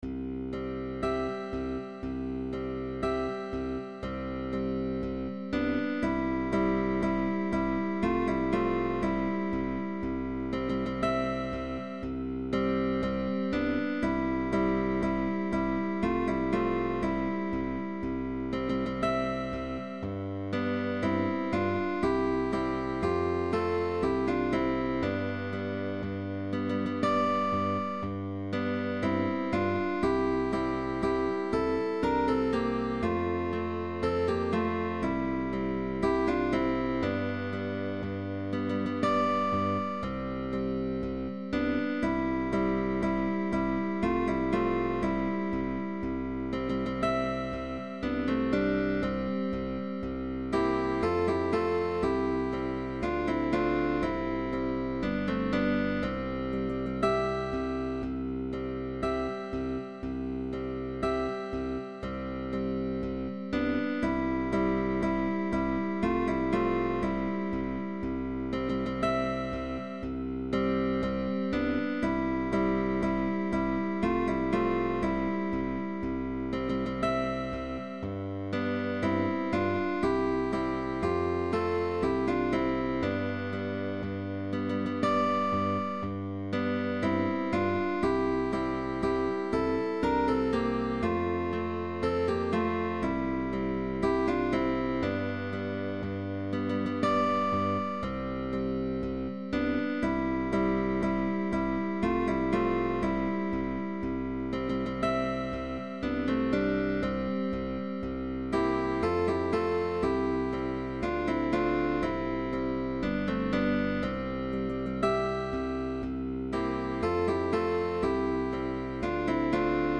GUITAR TRIO
Guitar Trios